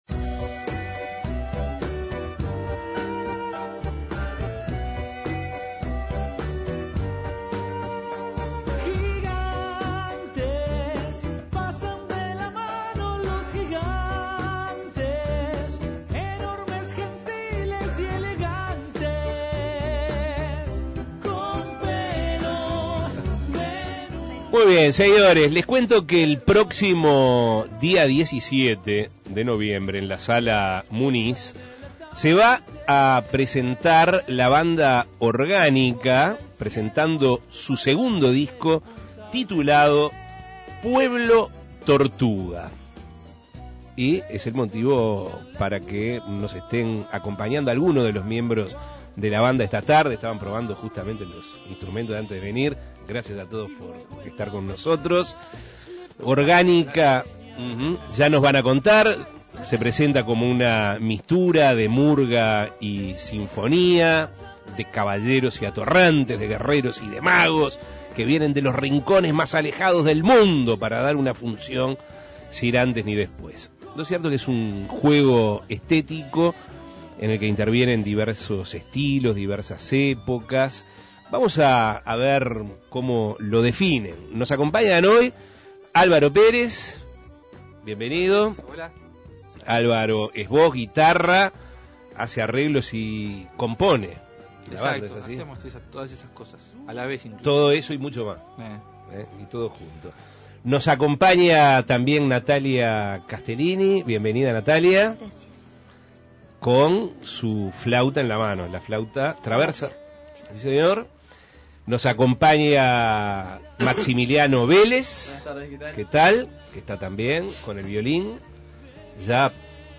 En esta edición de Asuntos Pendientes, se entrevistó a algunos de los miembros del grupo.